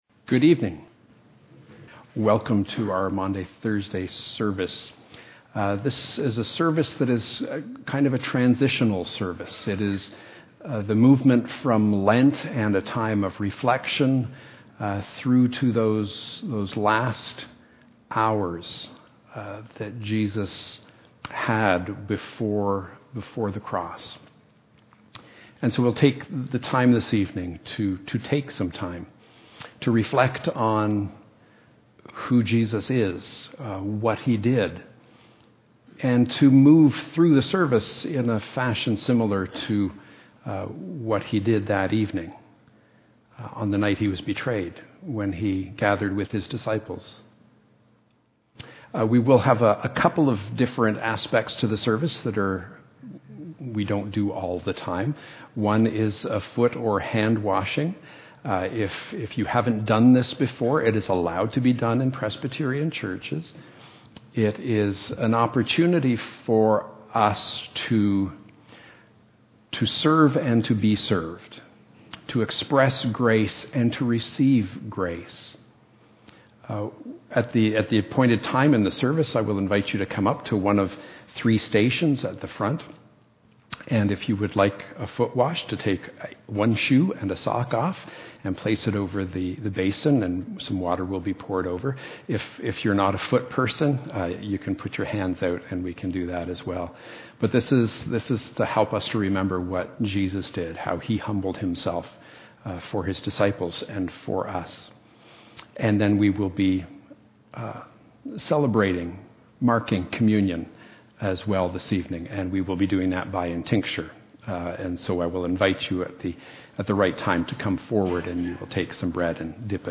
Sermons - St. Paul's Presbyterian Church